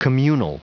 Prononciation du mot communal en anglais (fichier audio)
Prononciation du mot : communal